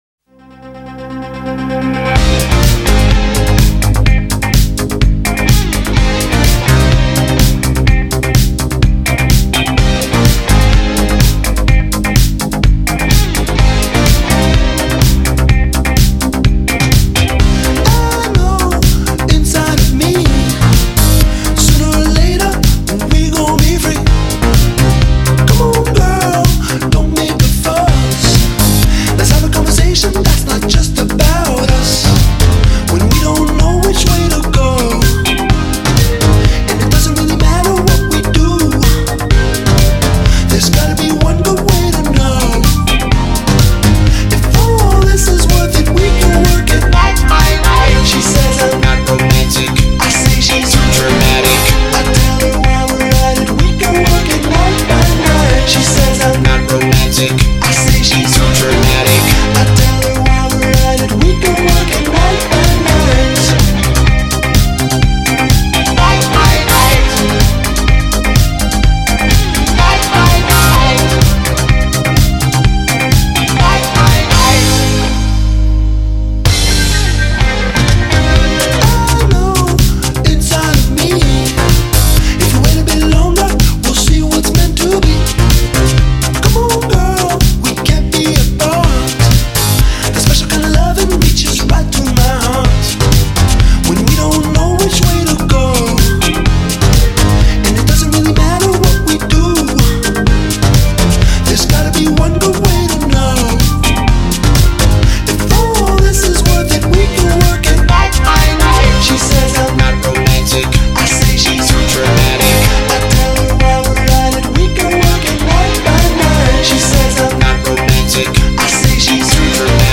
“Electrofunk”.